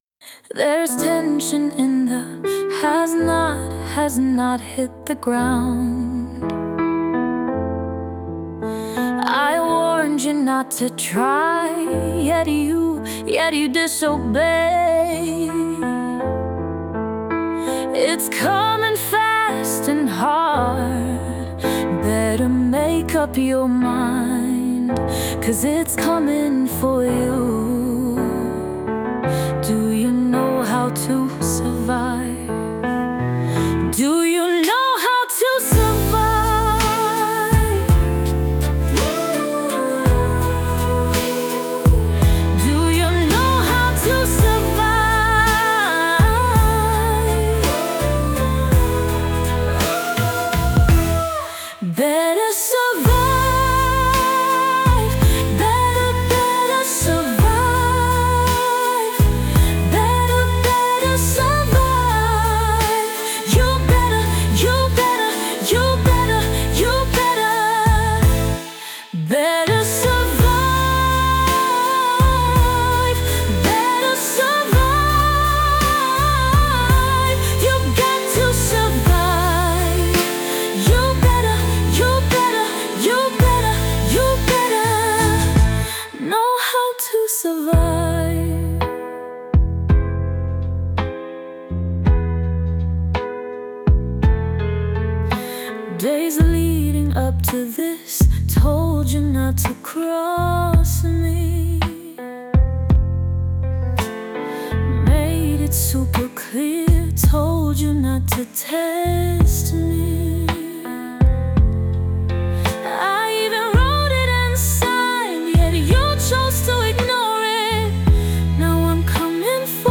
Just make the melody like the piano sound less like complicated like a creative classical